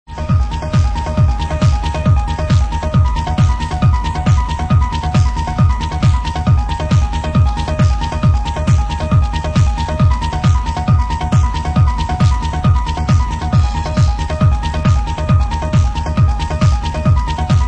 02) Other old Trance track from 1998, pls help!!!